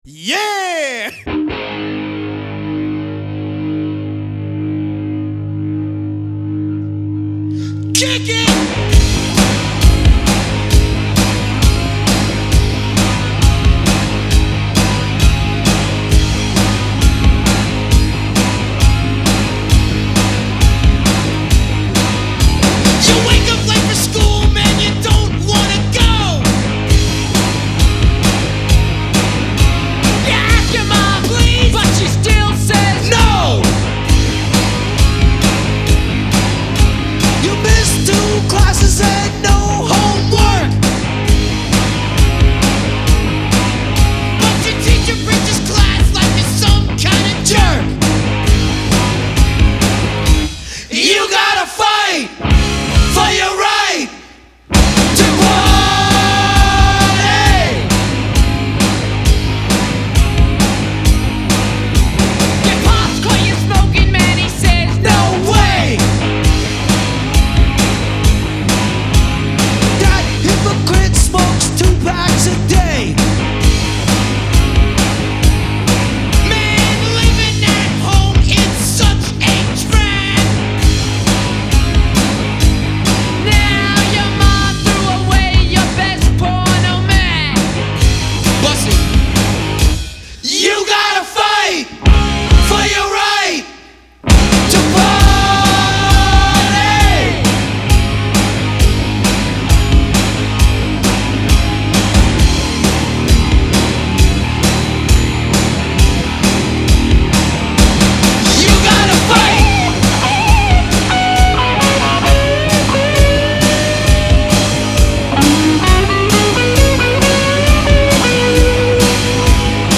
Hip-Hop/Rap heavy metal